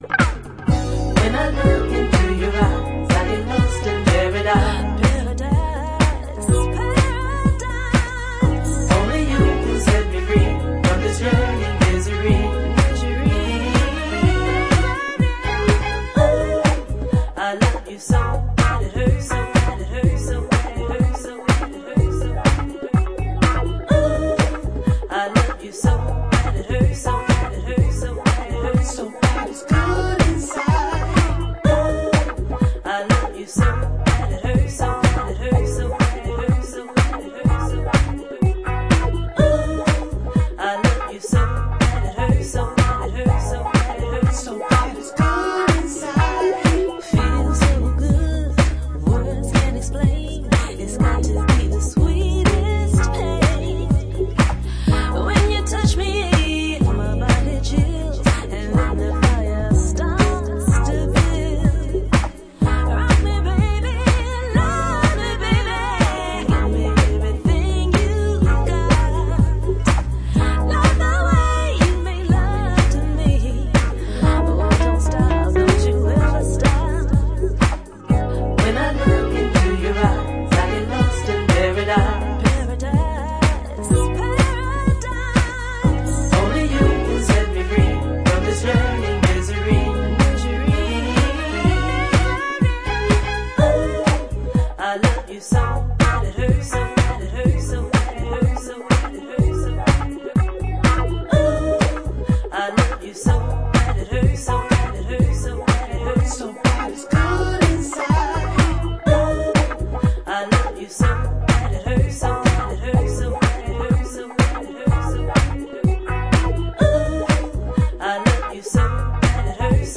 Deep soulful & funky housetrax
House Detroit